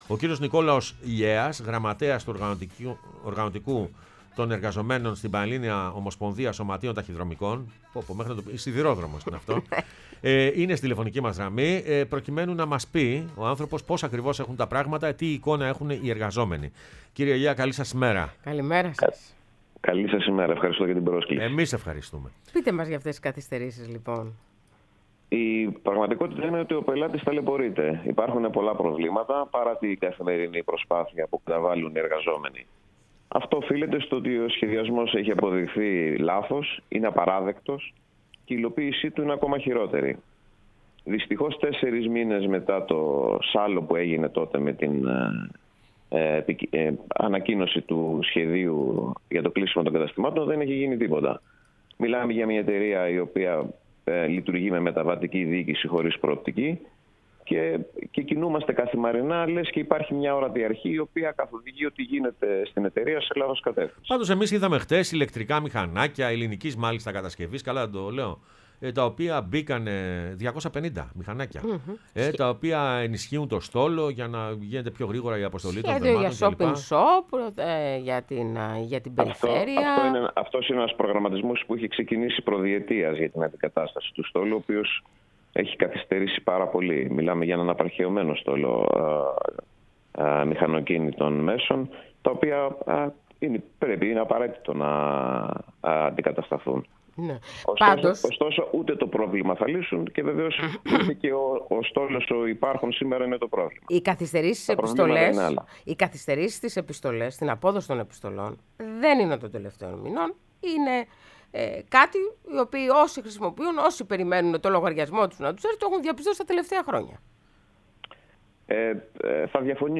μίλησε στην εκπομπή «Πρωινές Διαδρομές»